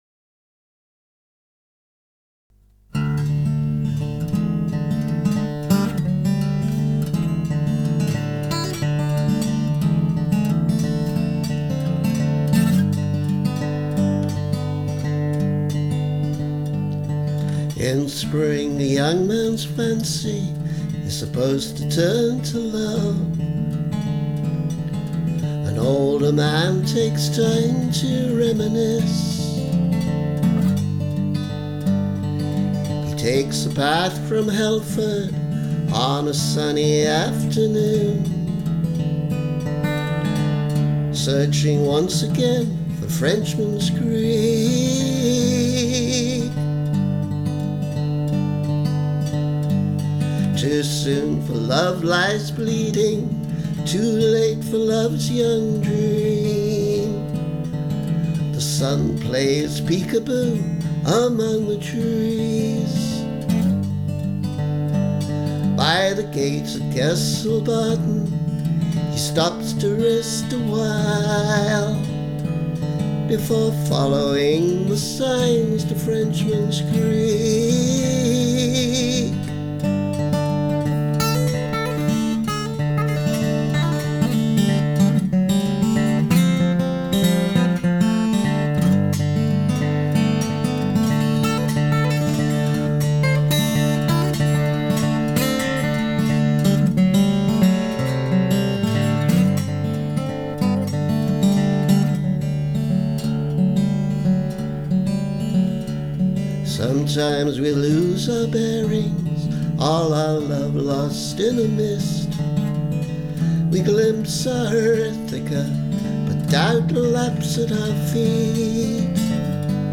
Demo version of a song that started to take shape while we were house-hunting in Kernow.